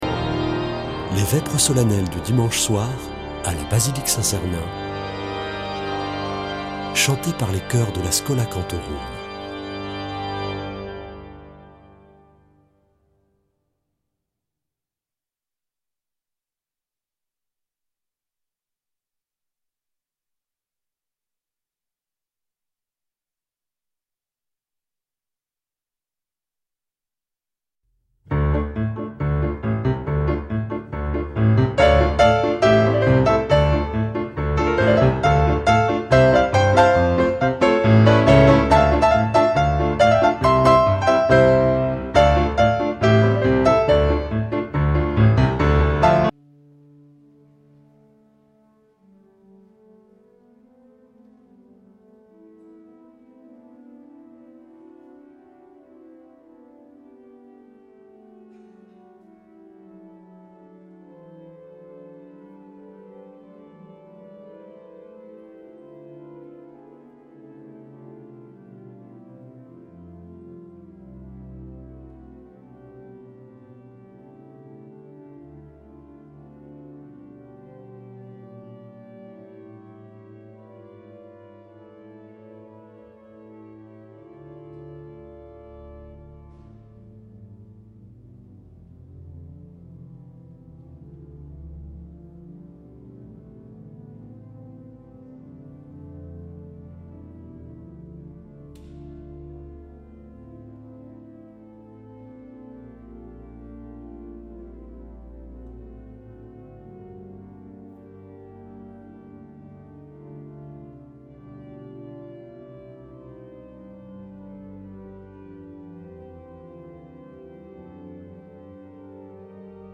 Vêpres de Saint Sernin du 08 déc.